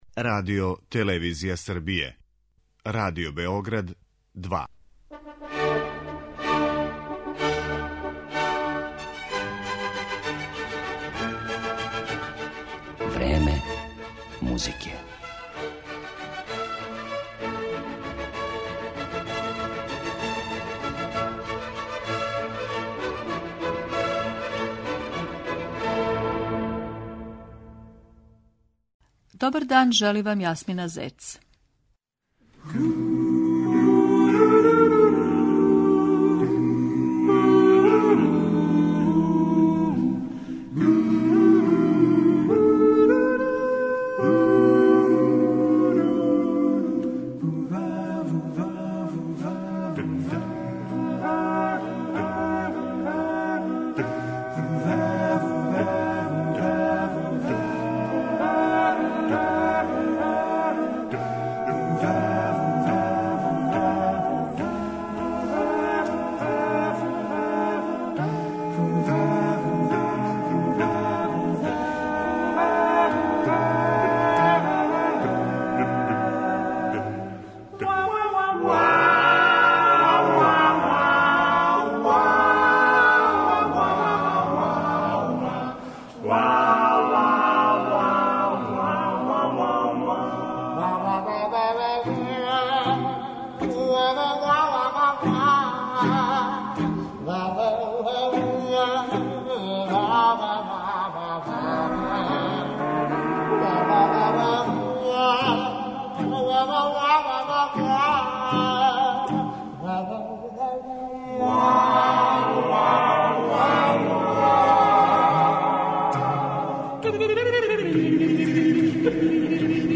Чланови овог вокалног секстета били су бројни британски врхунски певачи тако да је висок извођачки стандард већ скоро пет деценија његов заштитни знак.